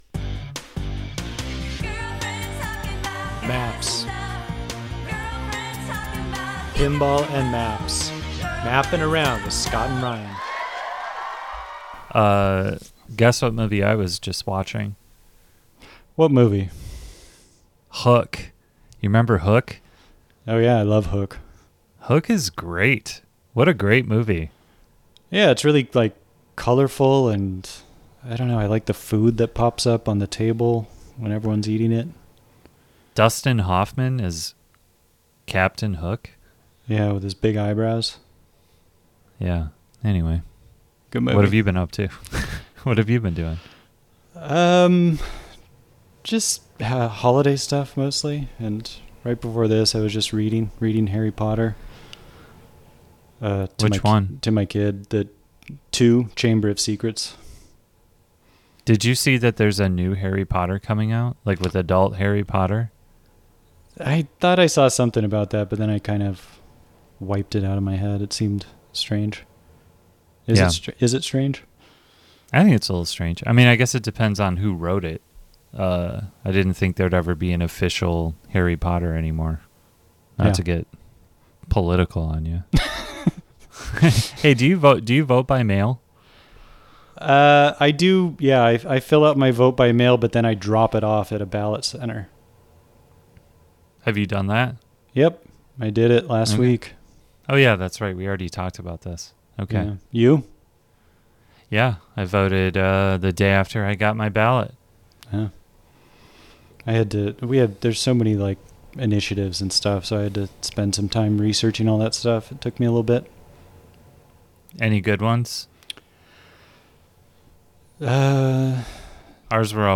talk to each on the phone for an hour